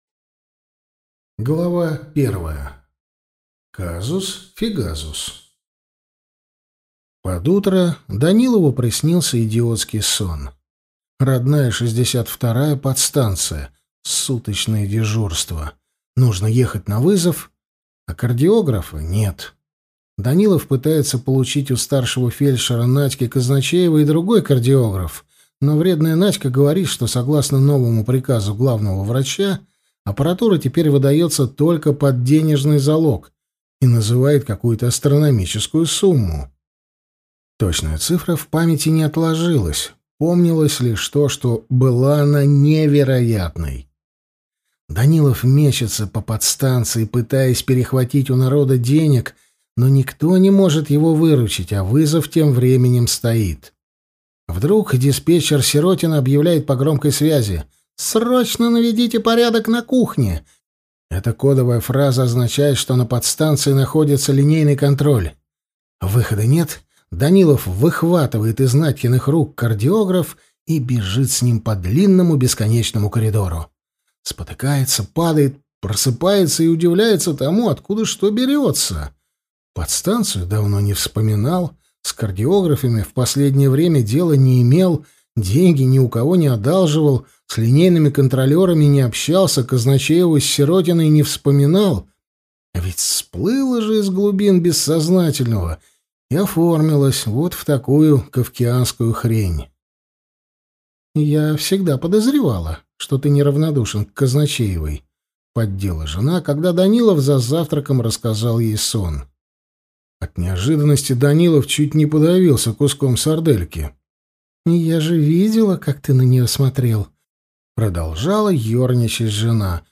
Аудиокнига Доктор Данилов и медицина будущего | Библиотека аудиокниг